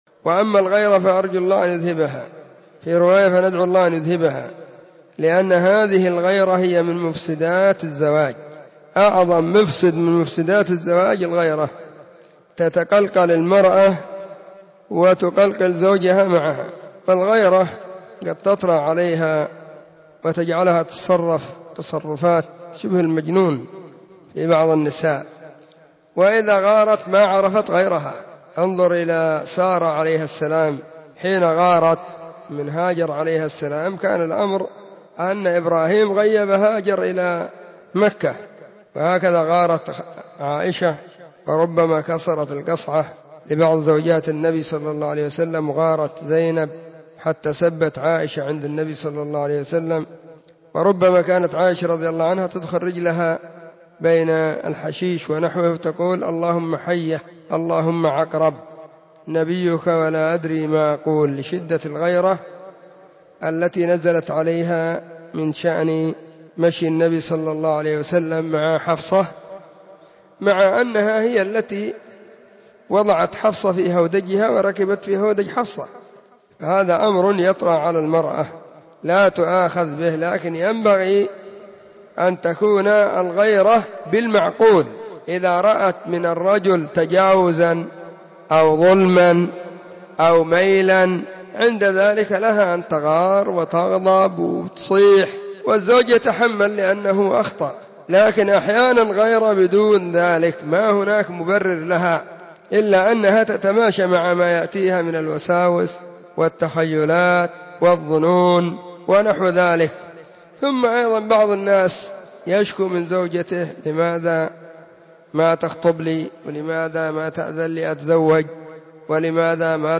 نصيحة قيمة بعنوان: حديث المساء في بيان الغيرة عند النساء